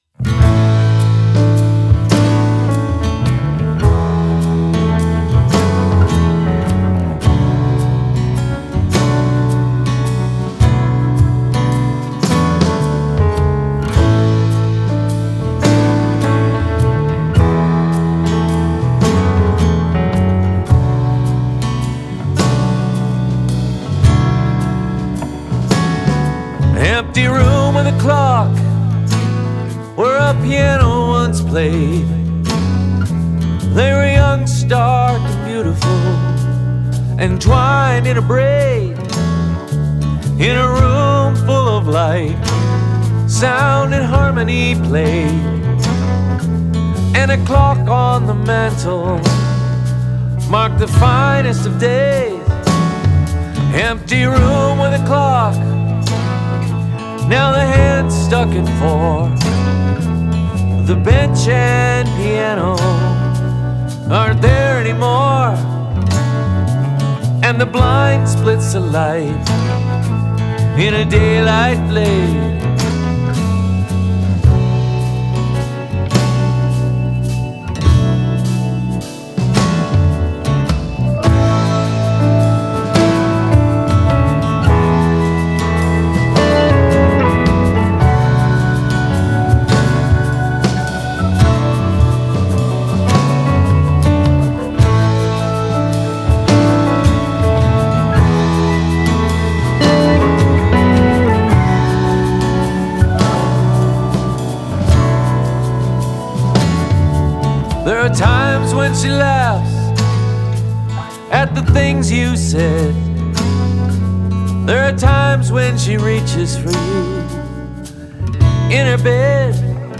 Drums, piano and some guitars
Vocals, lead guitars, bass
some Hammond and piano
Empty Room with a Clock - WIP
Recorded mixed and mastered with various versions of Ardour 8.
I also hear rim clicks in the vocal verses and it’s not clear to me how the drummer can change sticks so quickly.
The only thing I would change is the bass during the verses, because the long notes are “hooting” too much over everything else there.
During the choruses the bass fits much better into the overall mix…
Love the voice. Very emotional.
Coming to « deeper » feelings, according to me, the midrange are too busy, with piano, organ, guitar and even the bass who lies, somehow, in this area.